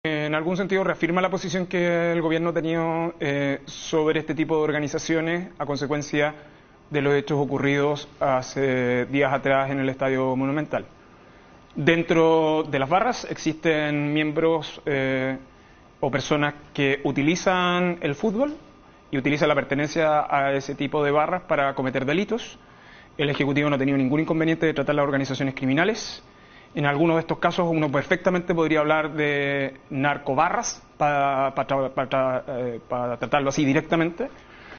“En algunos de estos casos, uno perfectamente podría hablar de narcobarras, para tratarlo así directamente”, indicó el secretario de Estado en entrevista con Mesa Central de T13.